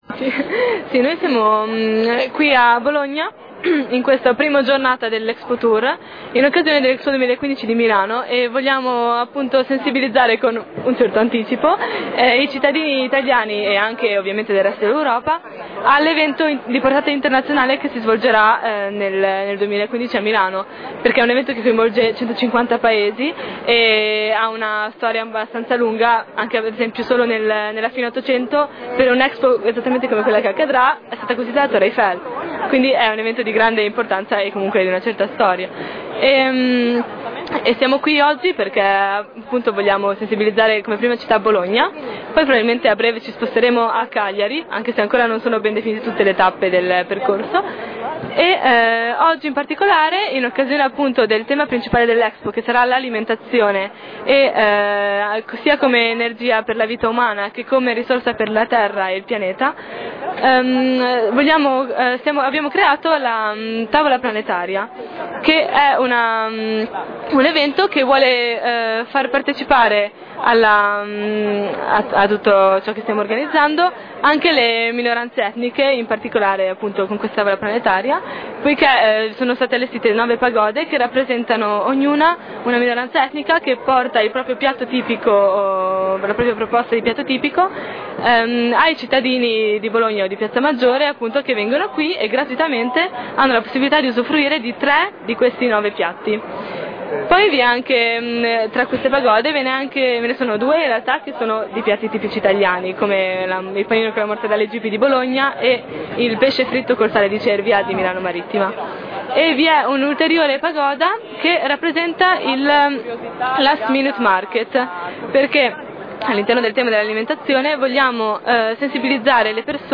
Ascolta i promoter di Expo 2015